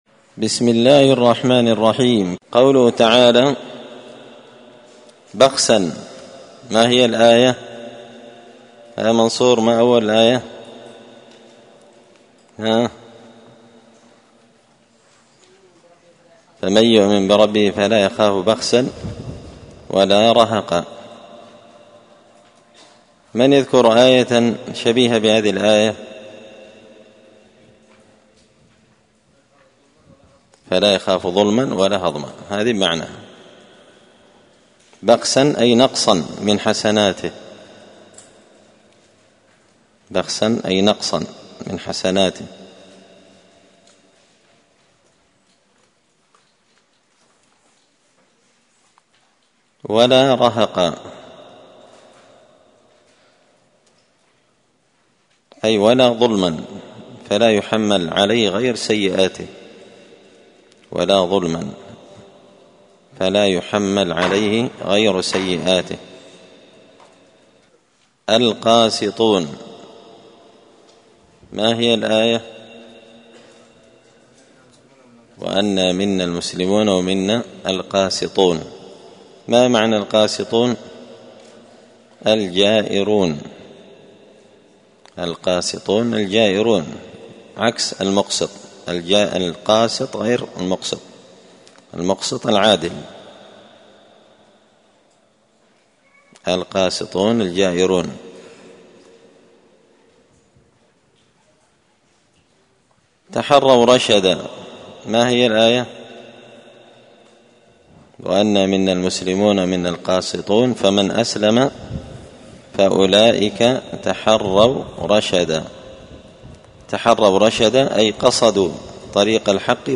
الثلاثاء 21 محرم 1445 هــــ | الدروس، دروس القران وعلومة، زبدة الأقوال في غريب كلام المتعال | شارك بتعليقك | 78 المشاهدات
مسجد الفرقان قشن_المهرة_اليمن